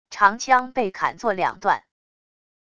长枪被砍做两段wav音频